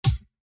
DHL HAT.mp3